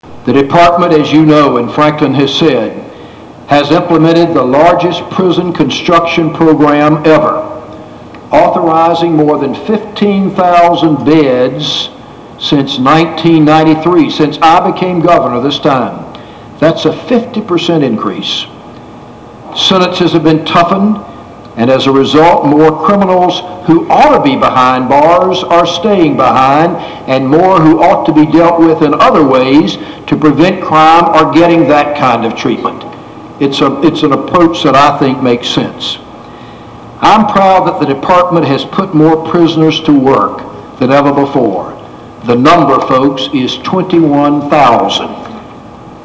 RALEIGH – Gov. Jim Hunt, legislators, Cabinet and Council of State members, prison superintendents and correctional officers from across the state were among the host of hundreds that filled the Old House Chamber today as Theodis Beck was sworn in as the new Secretary of Correction.